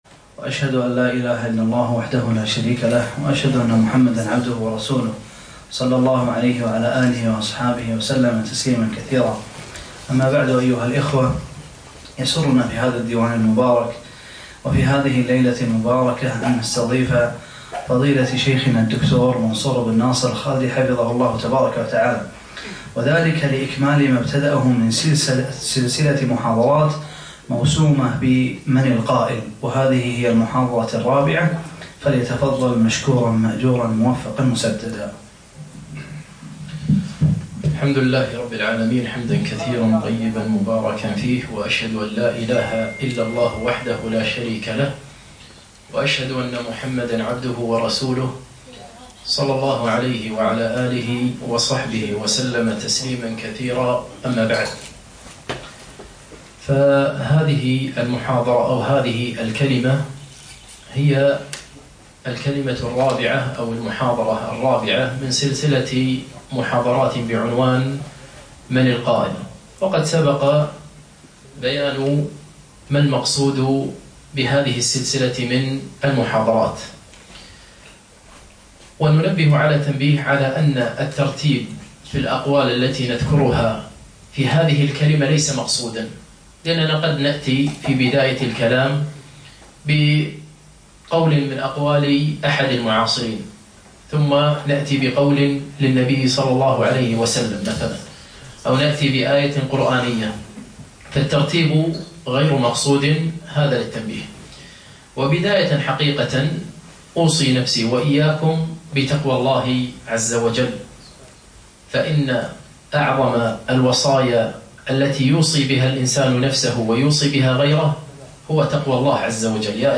4- من القائل ؟ - المحاضرة الرابعة